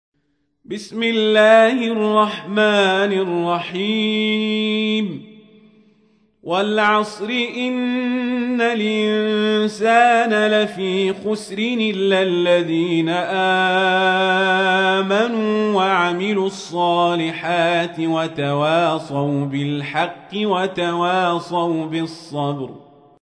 تحميل : 103. سورة العصر / القارئ القزابري / القرآن الكريم / موقع يا حسين